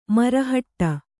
♪ marahaṭṭa